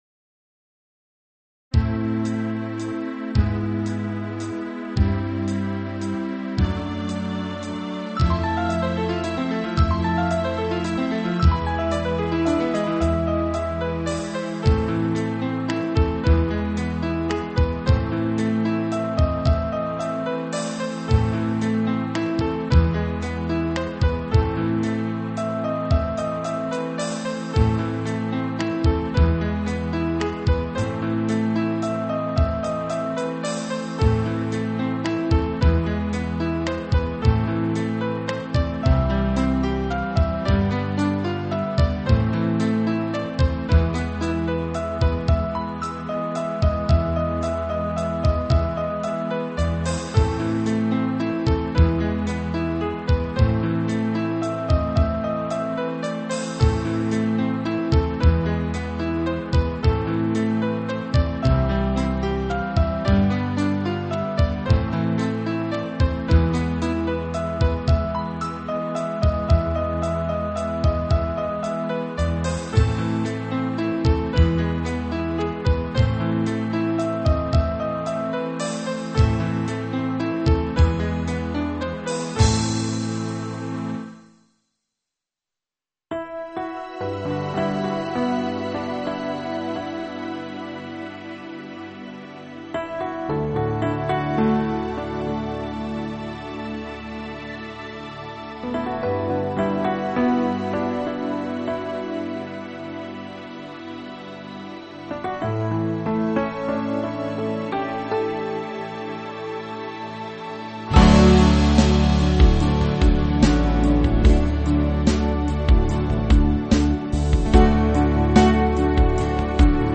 舞曲类别：3D全景环绕